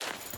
Dirt Chain Jump.wav